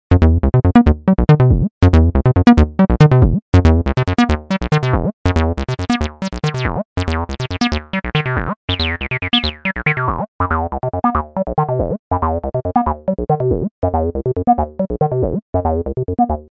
Un freeware très réussi qui propose, outre le séquenceur d'origine de la TB, des paramètres pour le moins alléchants : distorsion, réverbération, filtres...